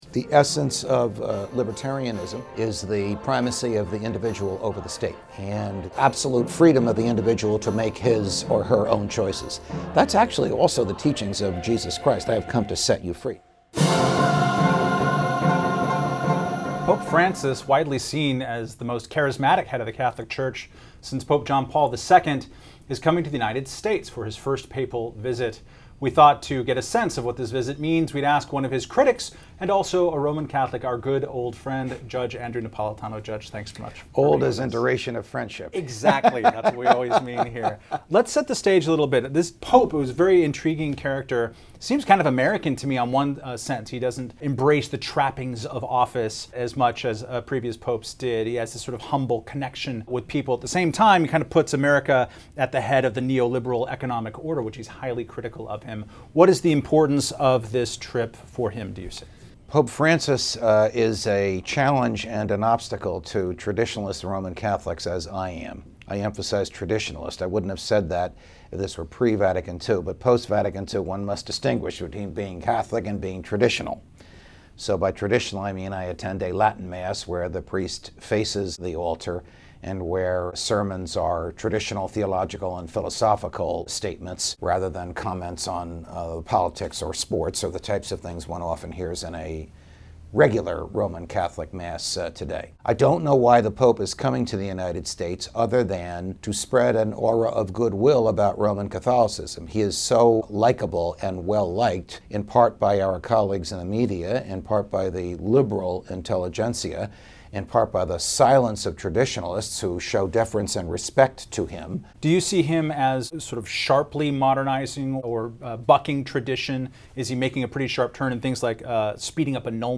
In a conversation with Reason's Editor in Chief, Matt Welch, the Judge discusses his frustrations with Pope Francis on the eve of his first Papal visit to the United States. Napolitano takes issue with the pope's exhortations on economic issues and Catholic tradition when it comes to allowing marriage annulments and permitting priests to forgive abortion
Music: "Ecce gratum" by MIT Concert Choir